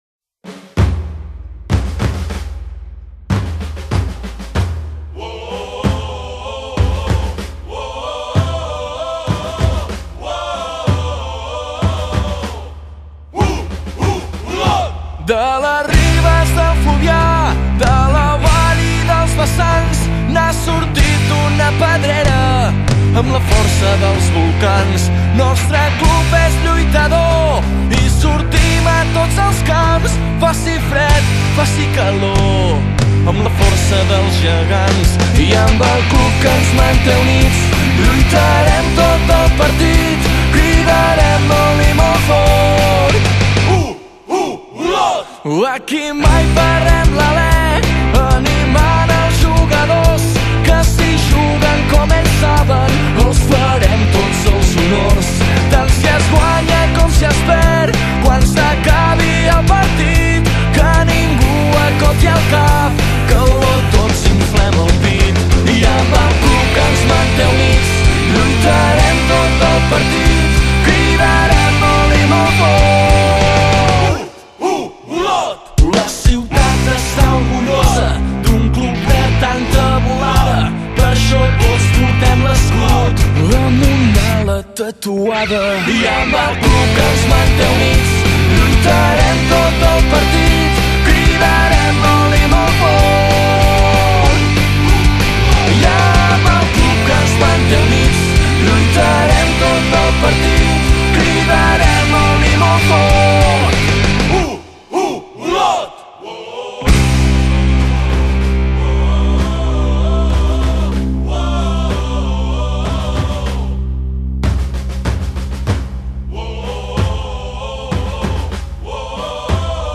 Veu i guitarra